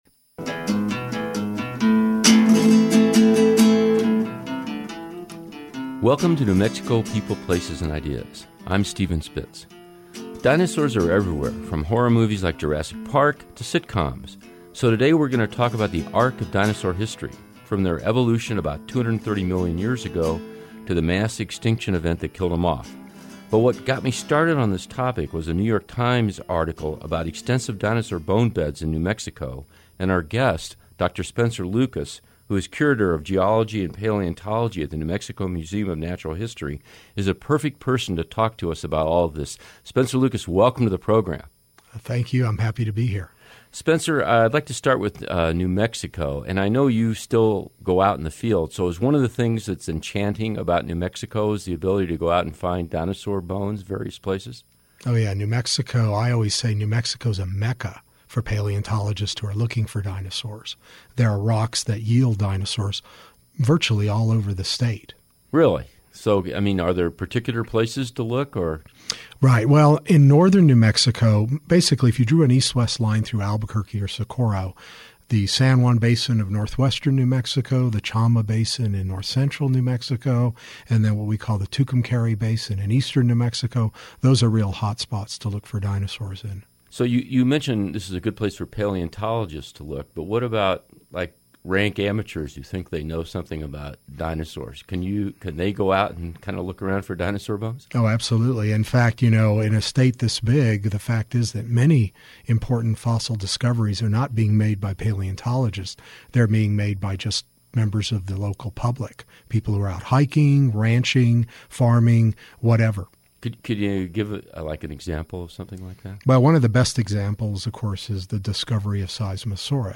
joined in the studio